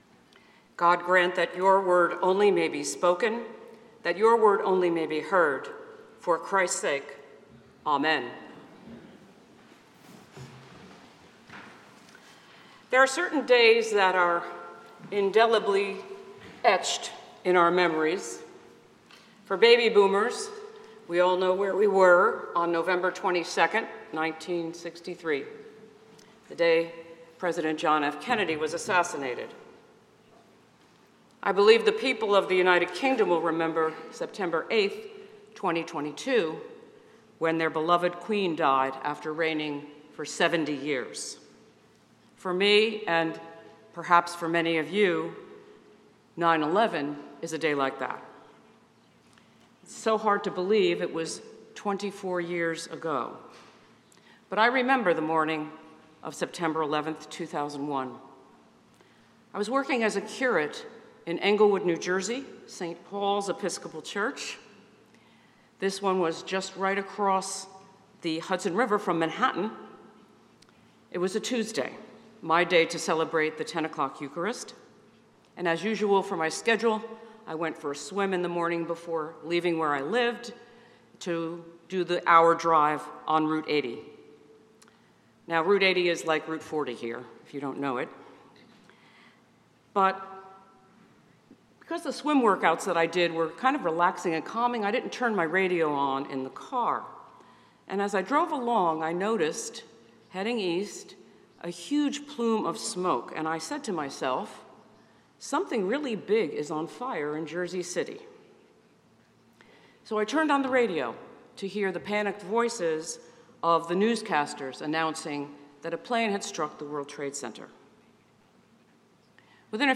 St-Pauls-HEII-9a-Homily-14SEP25.mp3